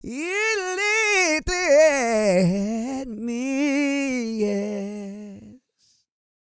E-GOSPEL 219.wav